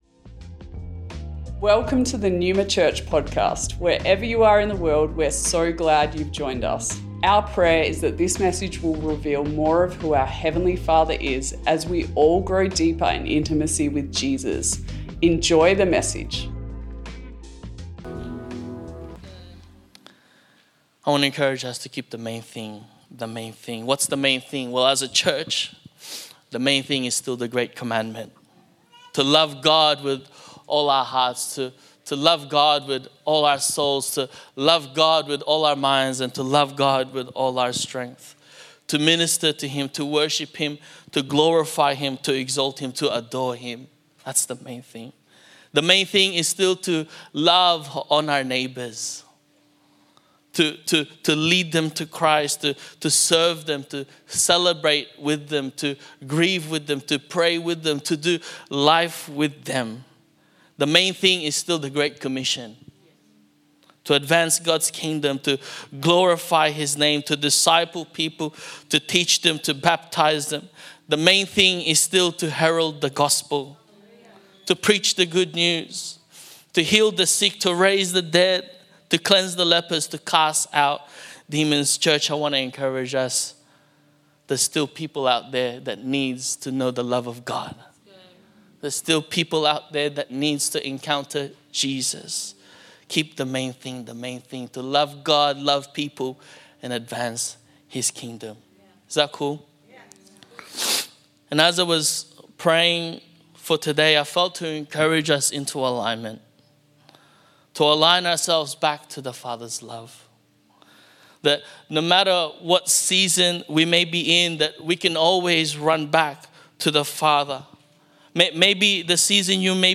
Originally recorded at Neuma Melbourne West